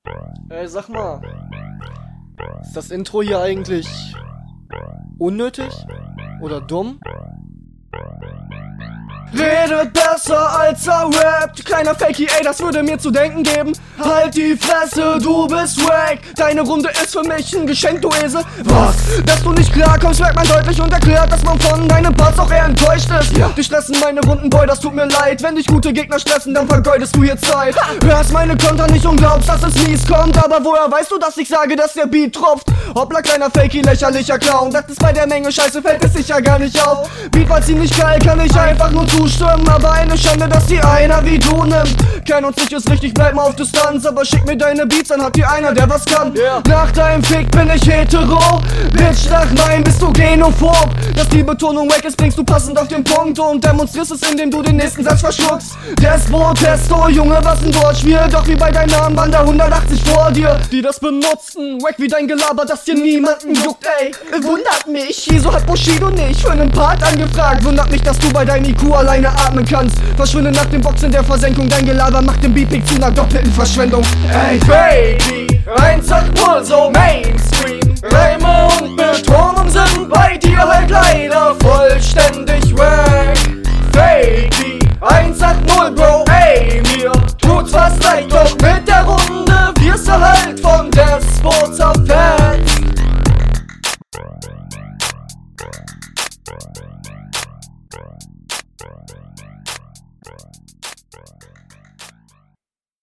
Der Gesang am Anfang ganz furchtbar gemischt .
Extrem gut gekontert sehr gut geflowt besser gemischt und deutlich pointierter geschrieben.